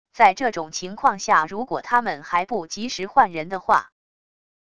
在这种情况下如果他们还不及时换人的话wav音频生成系统WAV Audio Player